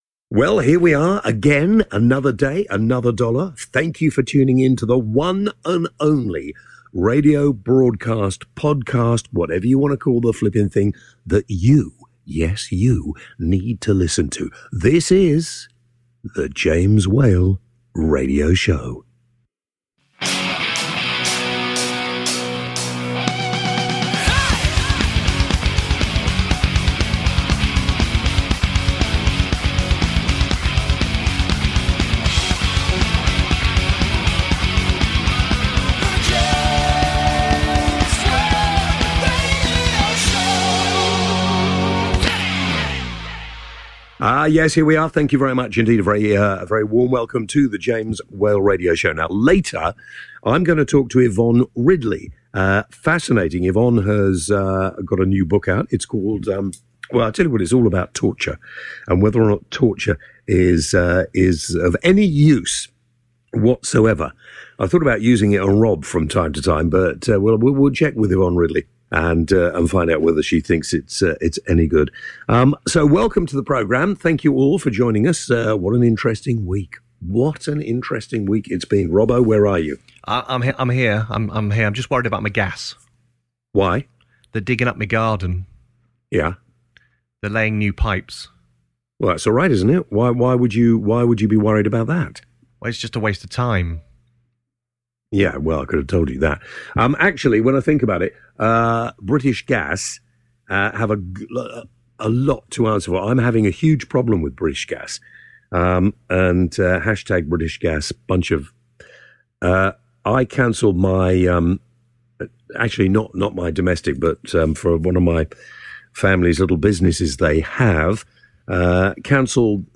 Yvonne Ridley is this weeks guest, Yvonne a journalist who was captured by the Taliban, and has since converted to Islam after release. We also get to find out about her new book “Torture: Does it work” (Available on amazon).